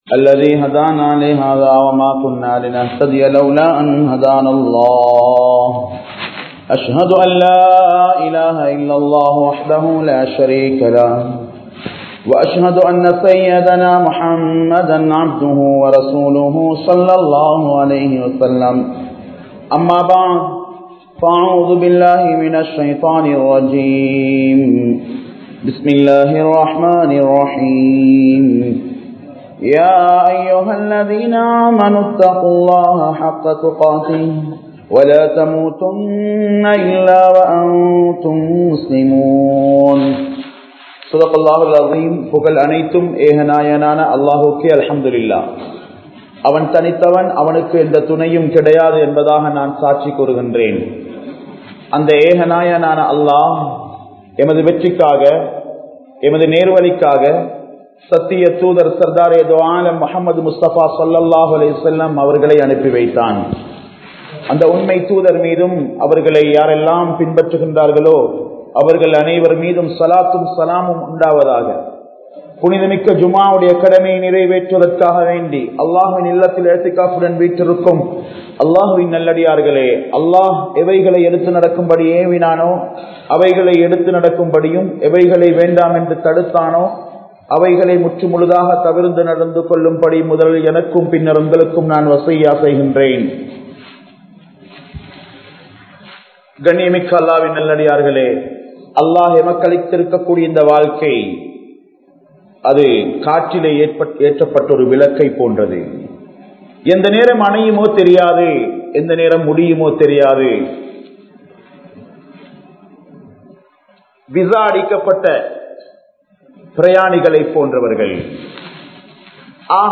O! Vaalifa Samoohame! (ஓ! வாலிப சமூகமே!) | Audio Bayans | All Ceylon Muslim Youth Community | Addalaichenai